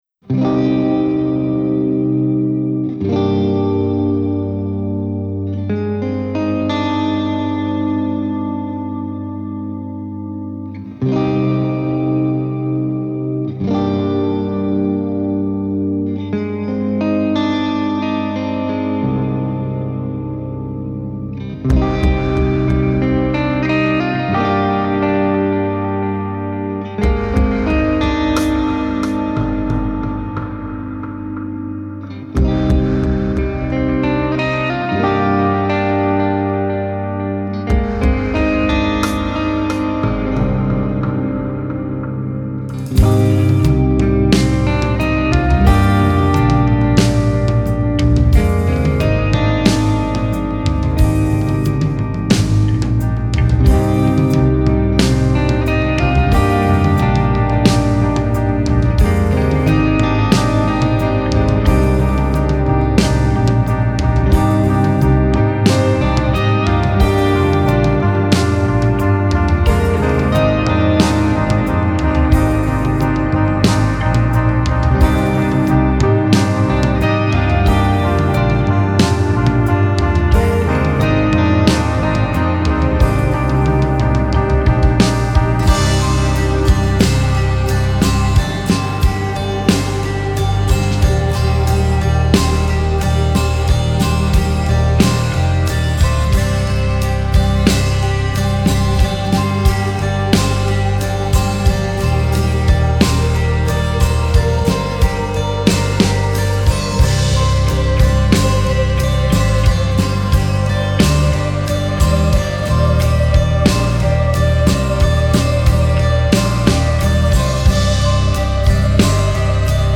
Melodic Doom/Death - Post-Metal